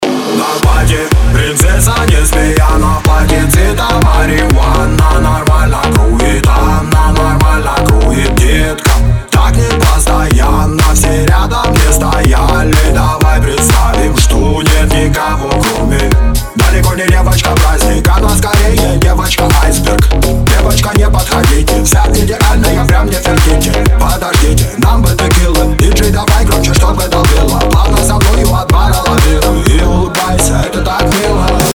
• Качество: 320, Stereo
мужской вокал
dance
Club House
club